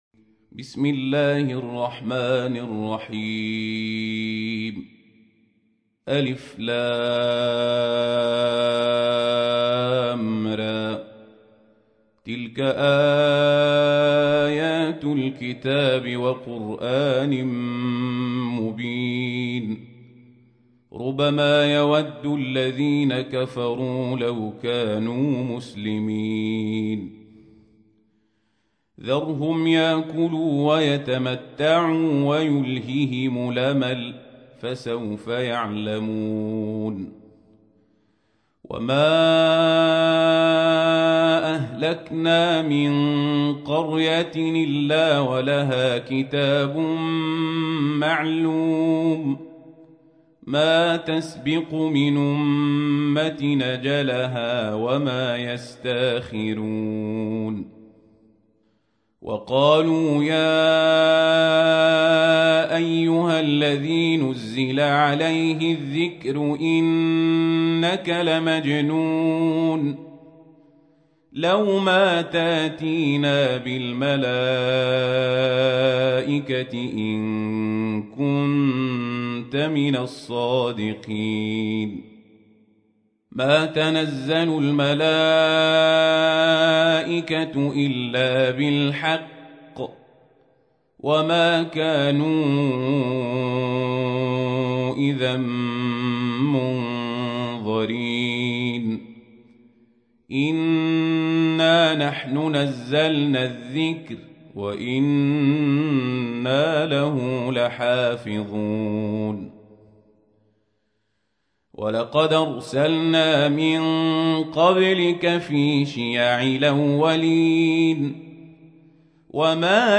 تحميل : 15. سورة الحجر / القارئ القزابري / القرآن الكريم / موقع يا حسين